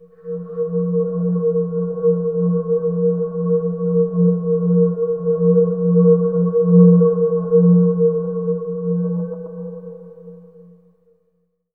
Index of /90_sSampleCDs/Trance_Formation/Atmospheric
17_AtmoPad_1_C.WAV